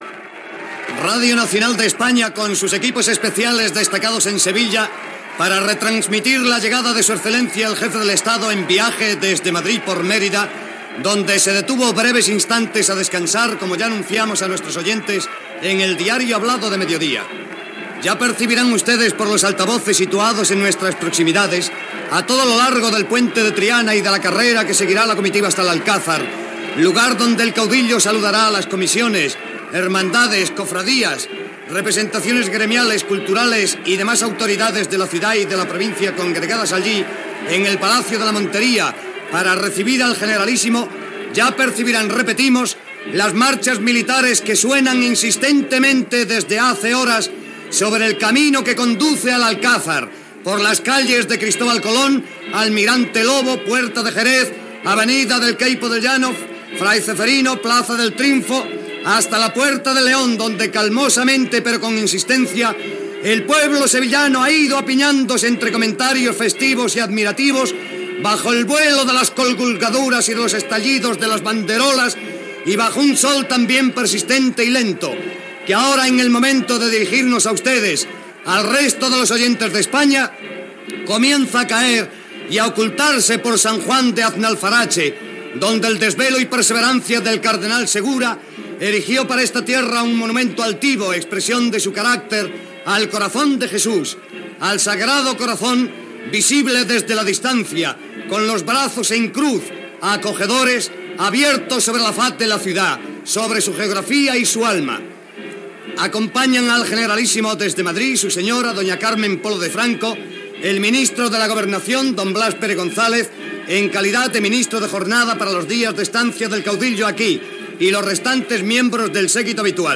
Recreació de la transmissió de l'arribada del "generalísimo" Francisco Franco a Sevilla, on hi passaria uns dies
Informatiu
Recreació feta per Radio Nacional de España anys després.